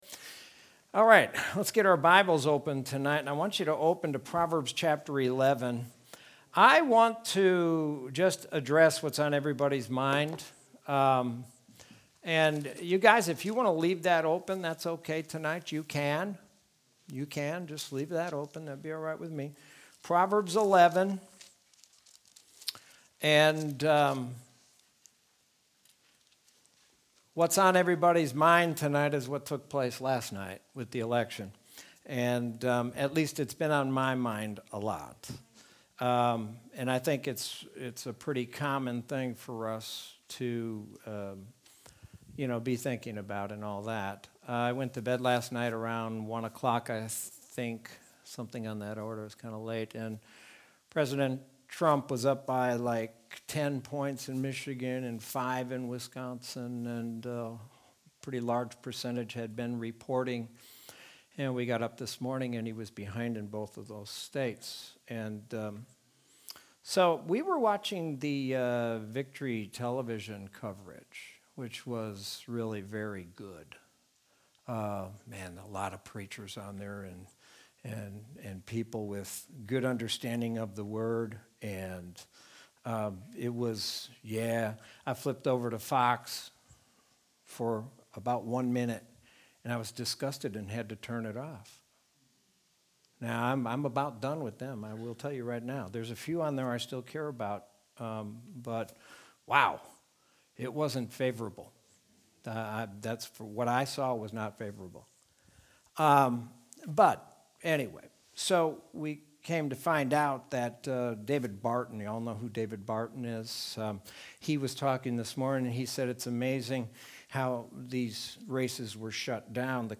Sermon from Wednesday, November 4th, 2020.